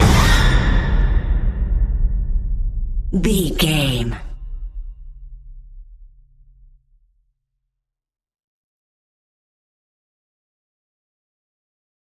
Psycho Style Horror Hit.
Sound Effects
Atonal
tension
ominous
dark
eerie
drums
percussion
strings